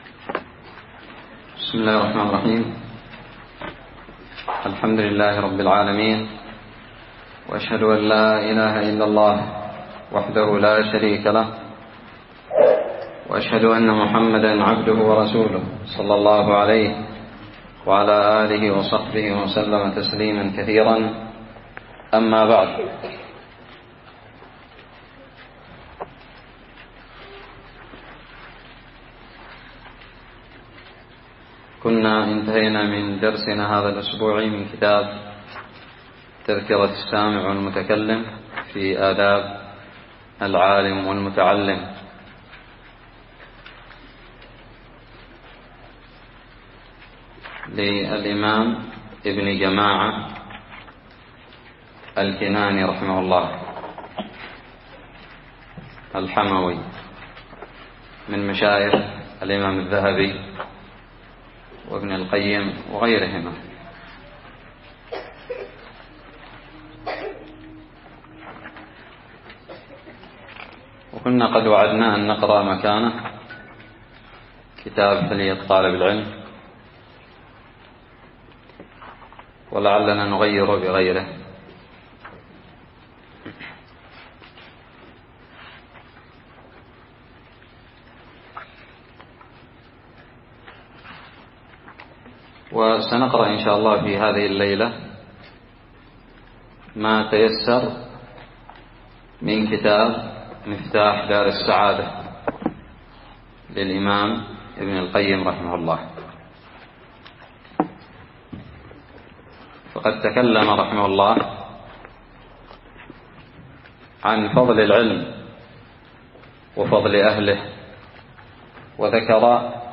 الدرس الأول من فضل العلم وأهله من مفتاح دارالسعادة
ألقيت بدار الحديث السلفية للعلوم الشرعية بالضالع